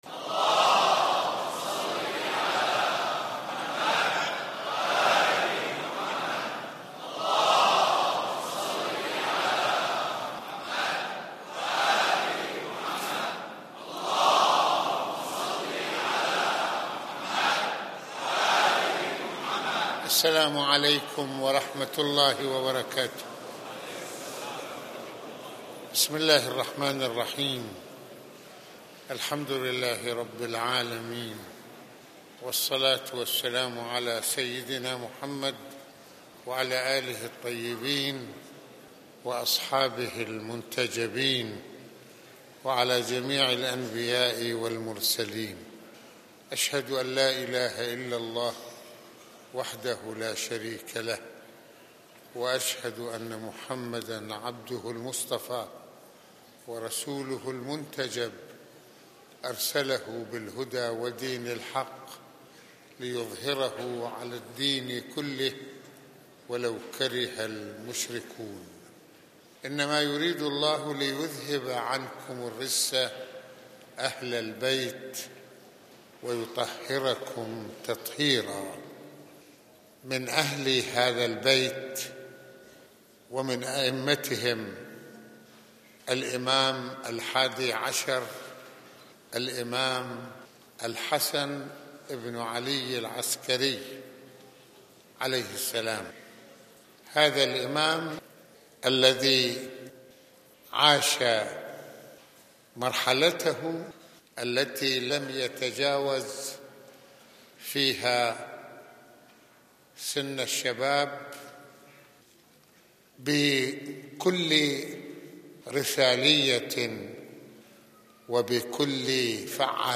- المناسبة : خطبة الجمعة المكان : مسجد الإمامين الحسنين(ع) المدة : 32د | 47ث المواضيع : مع ذكرى وفاة الإمام العسكري(ع): رحل الإمام وبقي الإسلام - دور الإمام العسكري التبليغي - تقدير الأعداء له(ع) - معالجة الانحرافات الفكرية - من وصاياه(ع) لشيعته.